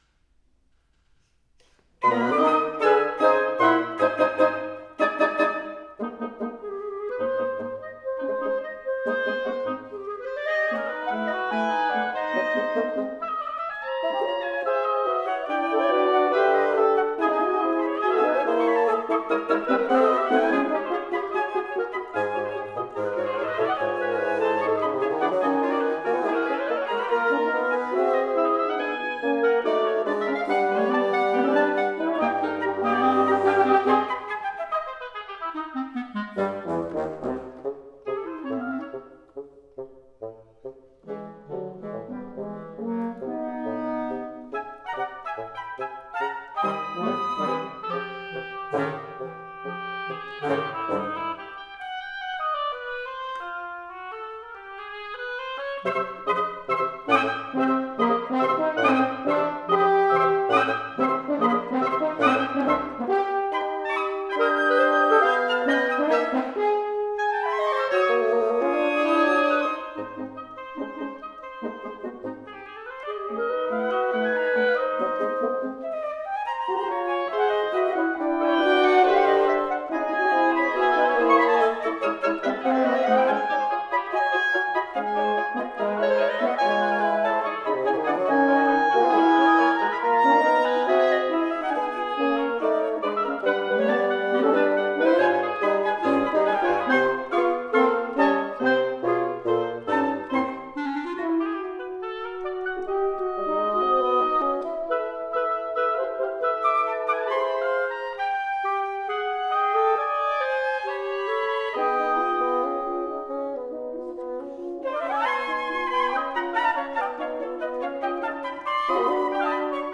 Flute
Oboe
Bb Clarinet
F Horn
Bassoon